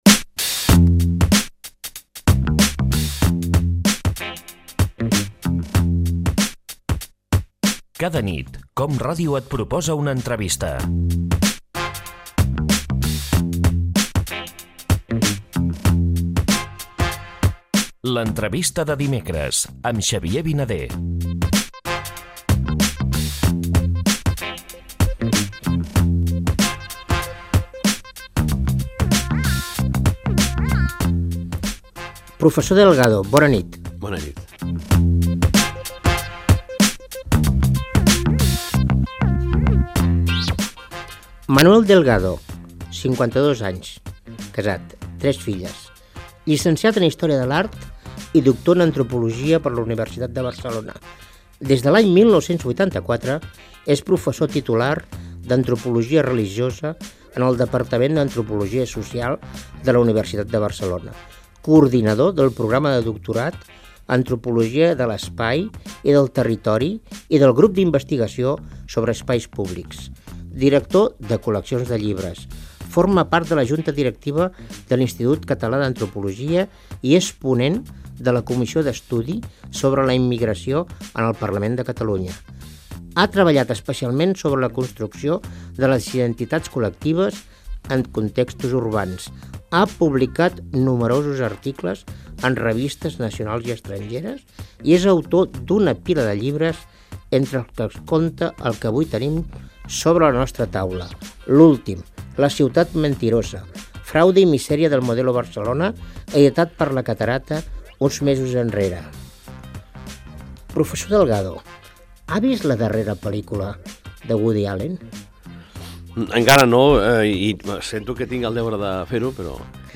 L'entrevista
Careta del programa i fragment d'una entrevista a l'antropòleg Manuel Delgado
Divulgació